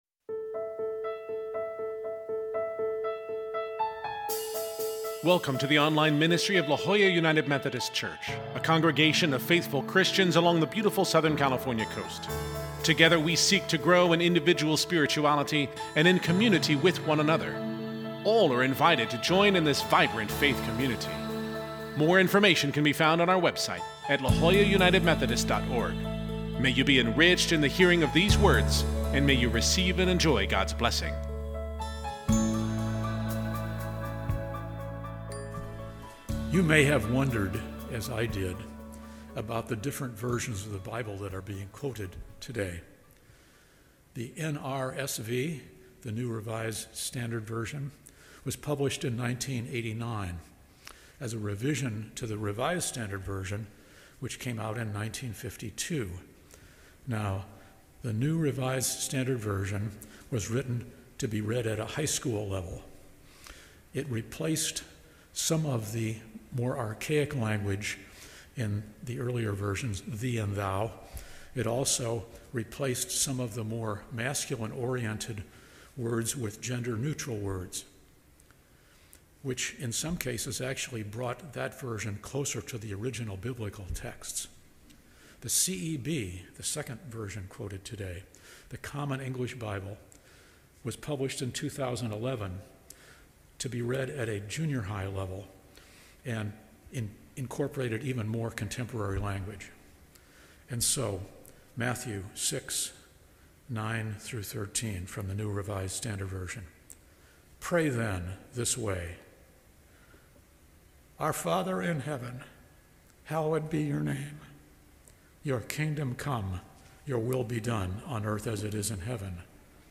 This is our final week for our sermon series following The Lord’s Prayer. This final week, we conclude with a section generally included by most Protestants that is commonly known as a doxology: for thine is the kingdom and the power and the glory forever.